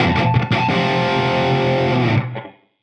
AM_HeroGuitar_85-F01.wav